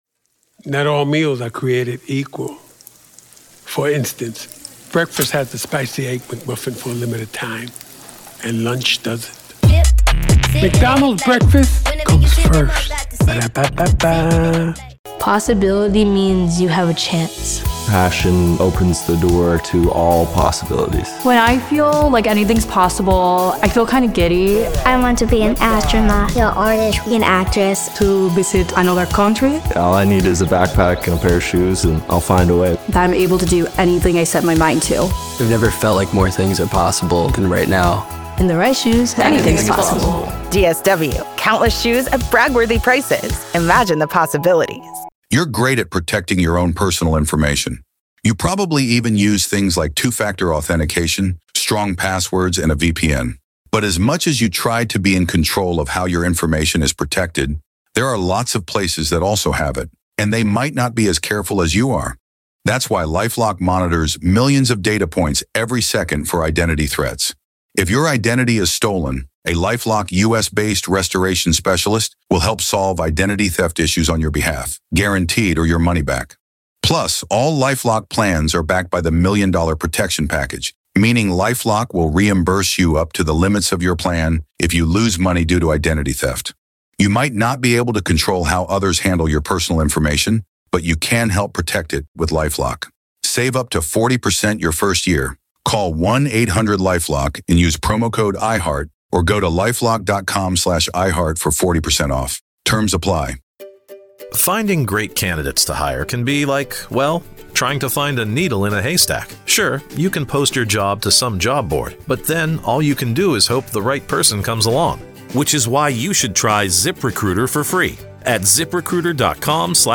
True Crime Today | Daily True Crime News & Interviews / What Do Jay-Z and Other Stars Know About Diddy’s ‘Freak Offs’?